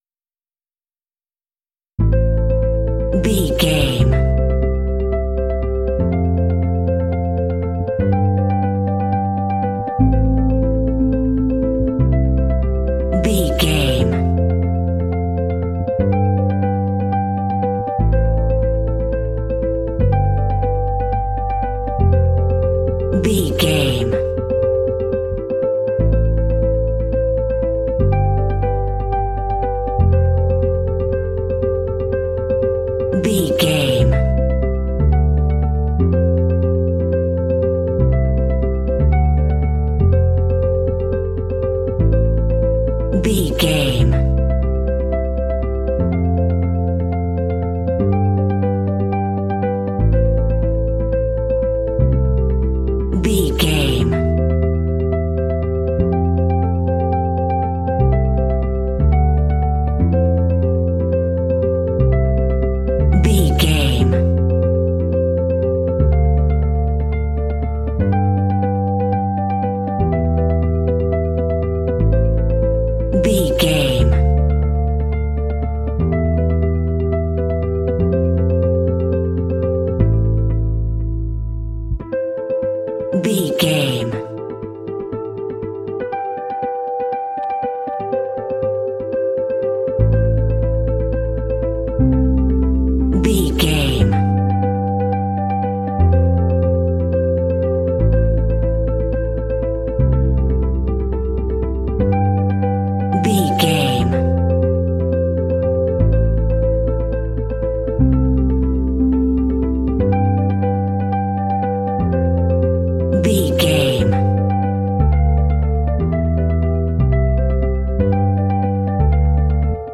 Aeolian/Minor
melancholy
contemplative
serene
peaceful
reflective
electric guitar
bass guitar
ambient
contemporary underscore